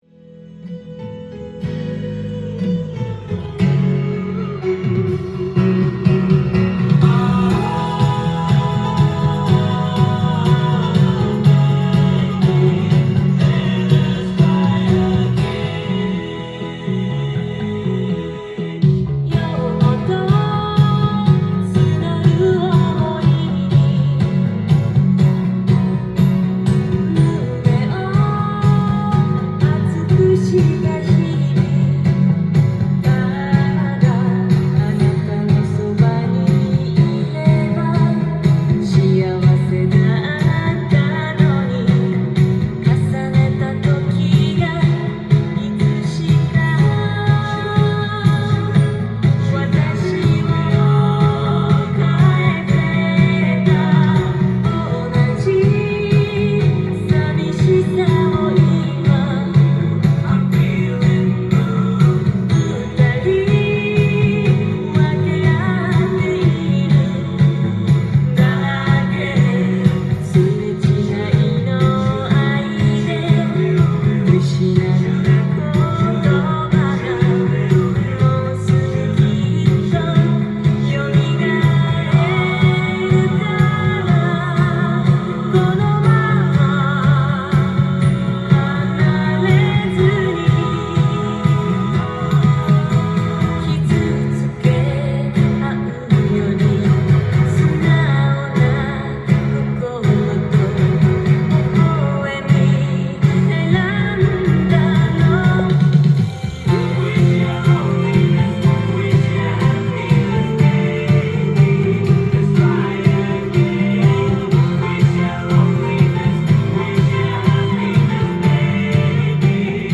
ジャンル：CITYPOP / POP
店頭で録音した音源の為、多少の外部音や音質の悪さはございますが、サンプルとしてご視聴ください。